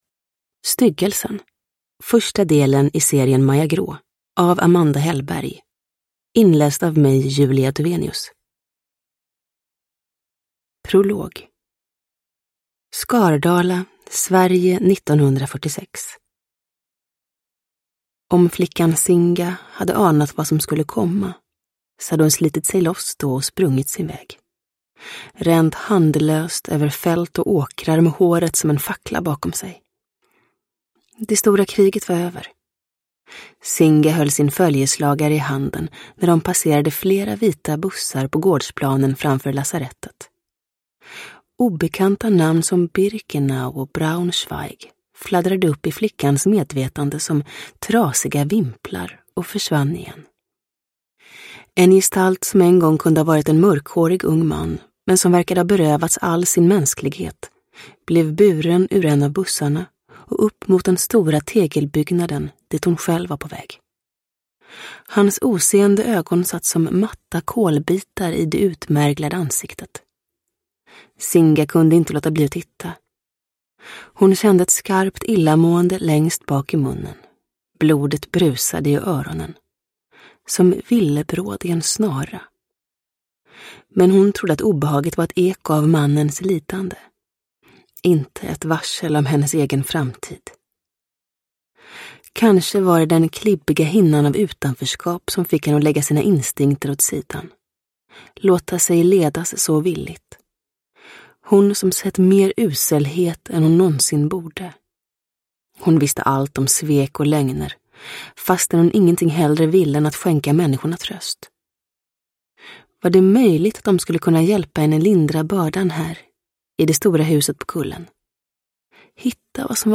Styggelsen – Ljudbok – Laddas ner